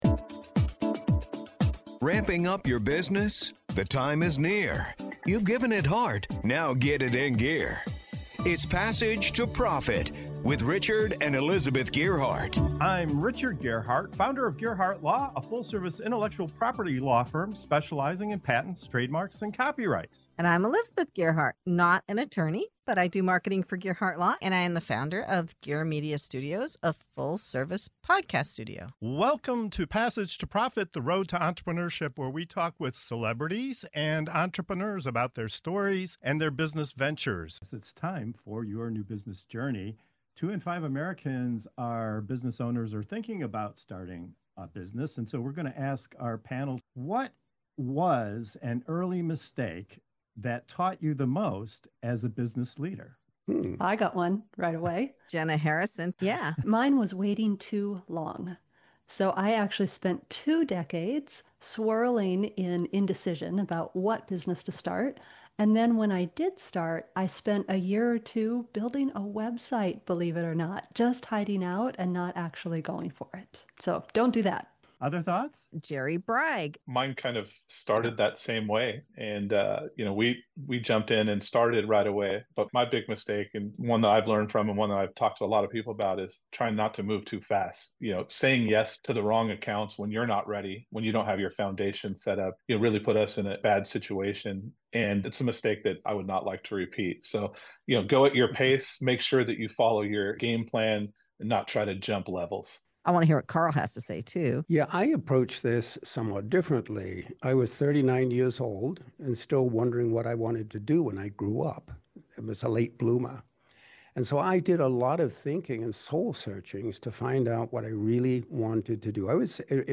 In this segment of "Your New Business Journey" on Passage to Profit Show, our panel of seasoned entrepreneurs opens up about the early mistakes that shaped their business journeys—from spending years stuck in indecision, to moving too fast and saying “yes” to the wrong opportunities, to discovering the values that truly drive success. With candid stories and hard-earned wisdom, they reveal the delicate balance between planning and action, and why missteps can be the most valuable teachers on the path to building a thriving business.